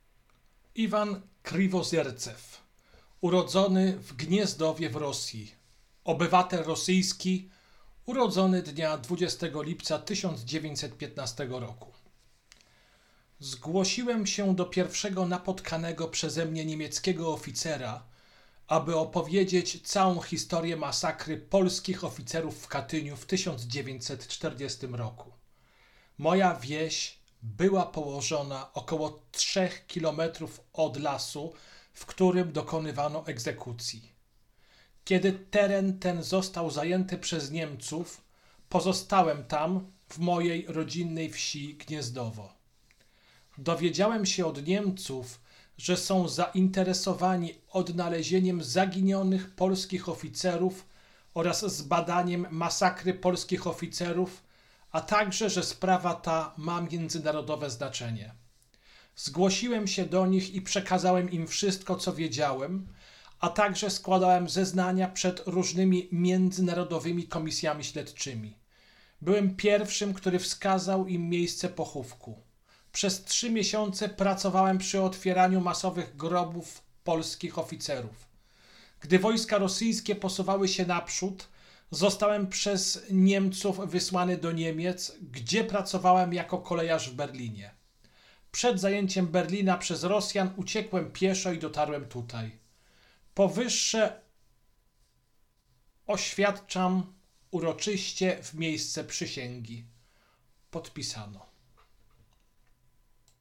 Kategoria: zeznanie świadka